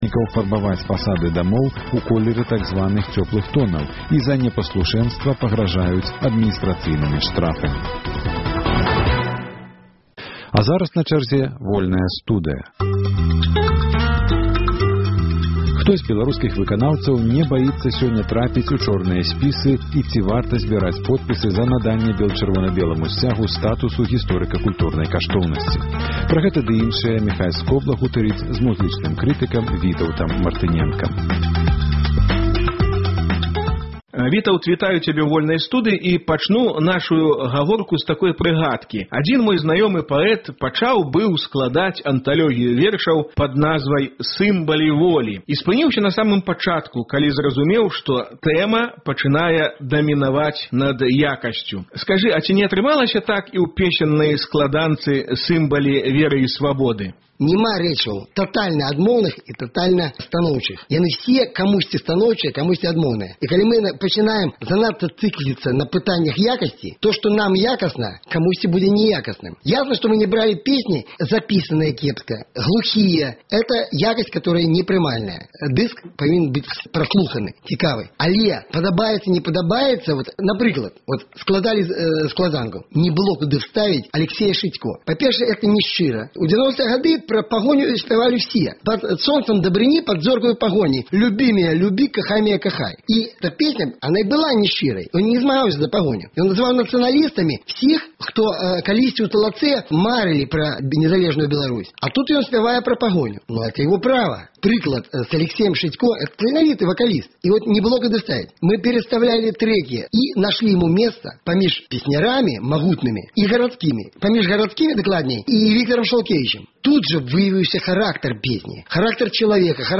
Гутаркі